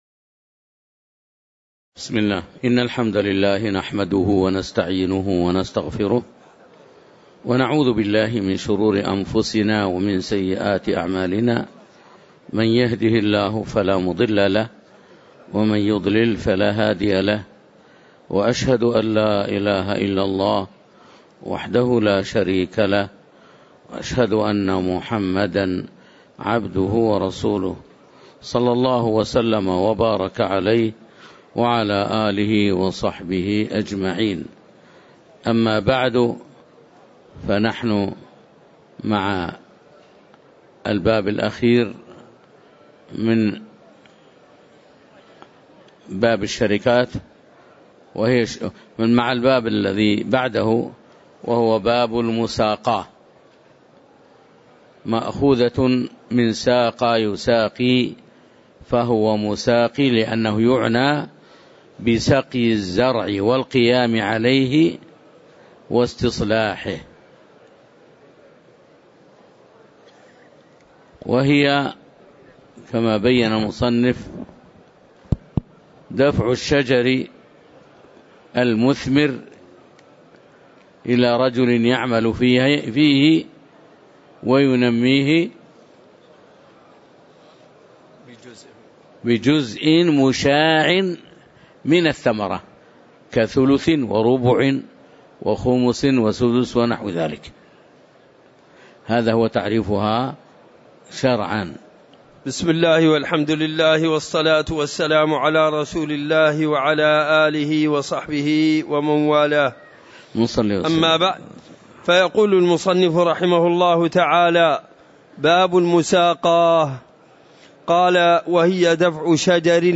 تاريخ النشر ٦ جمادى الأولى ١٤٤١ هـ المكان: المسجد النبوي الشيخ